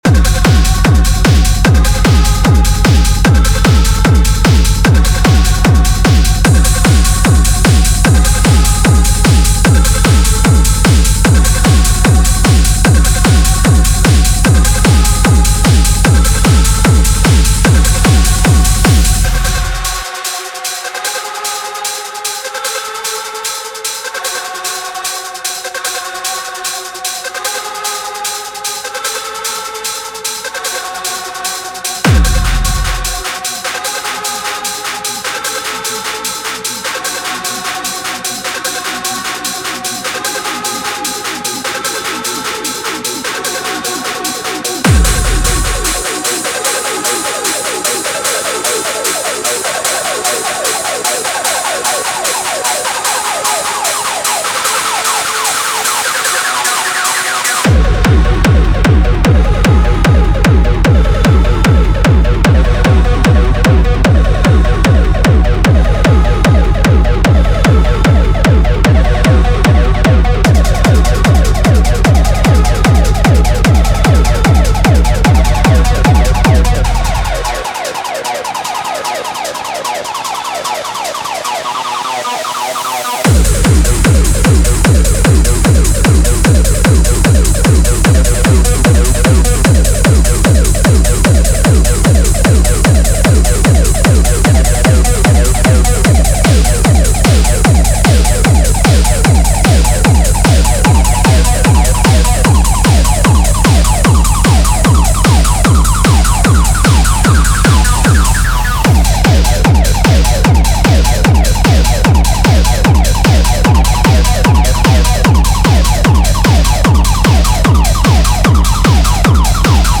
Styl: Techno, Hardtek/Hardcore